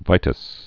(vītəs, -tə-sĭz)